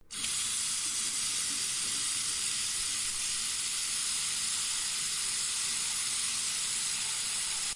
水槽
描述：Sennheiser ME40。放大H6。地点：NSCAD大学。哈利法克斯，NS。加拿大。
声道立体声